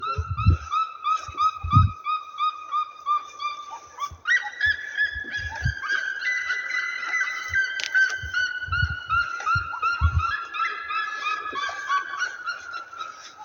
Seriema (Cariama cristata)
Nome em Inglês: Red-legged Seriema
Localidade ou área protegida: Eco Pousada Rio dos Touros, Urupema, Sc, Brasil
Condição: Selvagem
Certeza: Fotografado, Gravado Vocal
CHUNA-PATAS-ROJAS.mp3